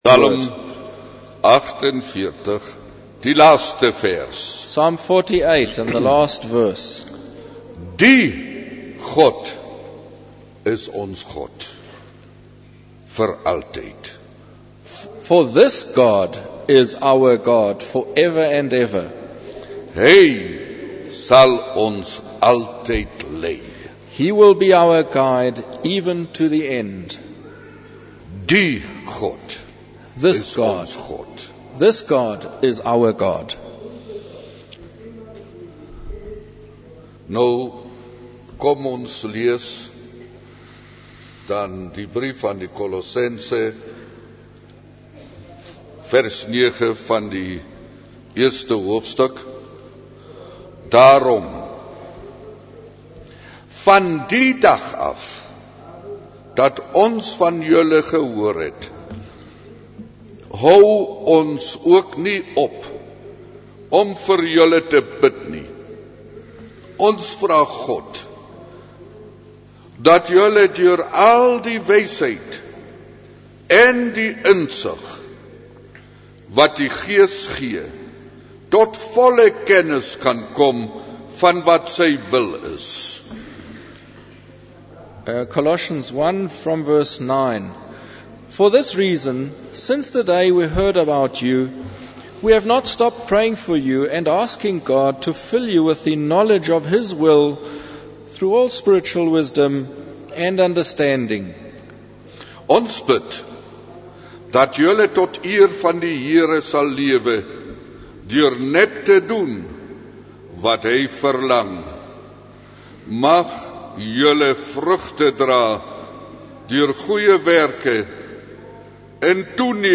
In this sermon, the speaker emphasizes three important points for believers to incorporate into their lives. The first point is to seek and understand God's will for their lives, as it is crucial for spiritual growth.